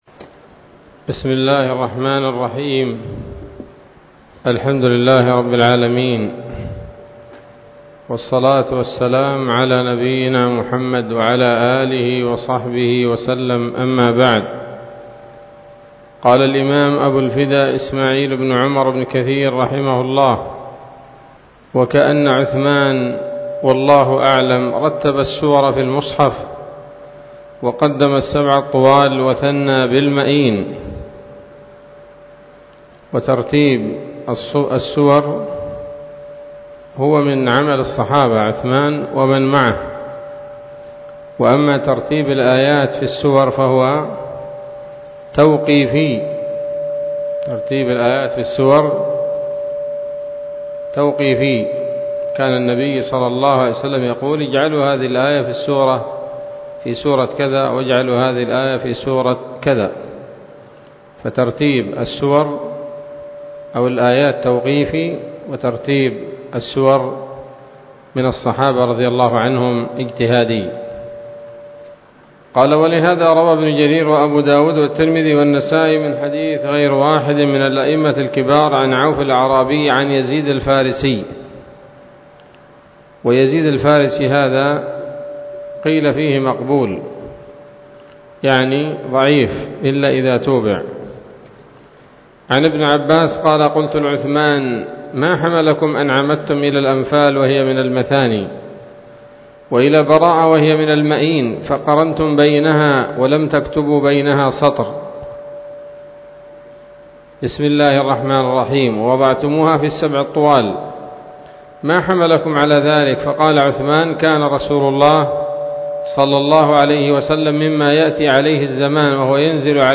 الدرس التاسع من المقدمة من تفسير ابن كثير رحمه الله تعالى